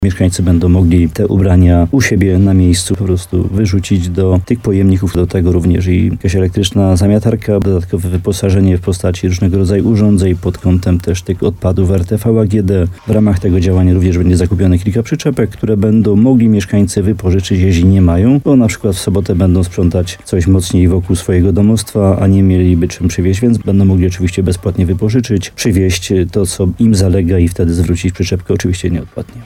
Jak powiedział w rozmowie Słowo za Słowo w RDN Nowy Sącz wójt gminy Łącko Jan Dziedzina, modernizacja budynków i otoczenia w większości będzie polegać na zakupie nowych sprzętów.